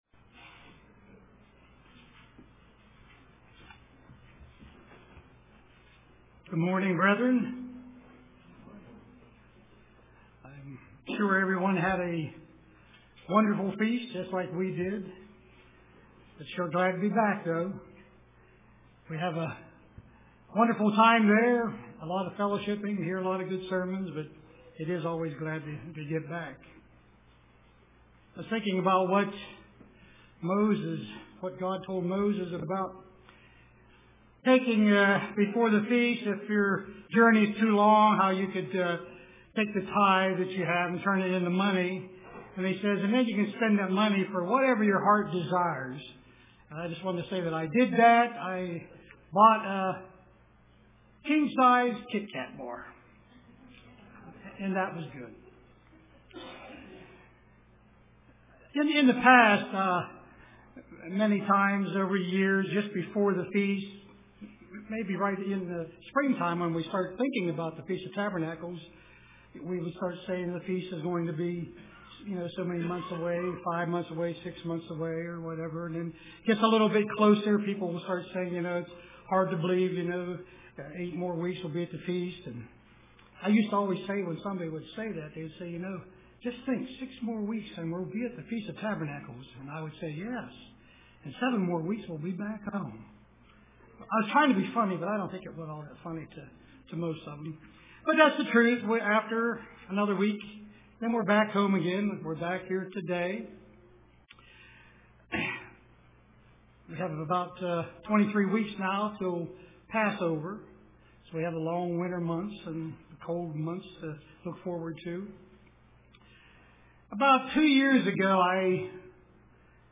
Print From Tabernacles to Passover UCG Sermon Studying the bible?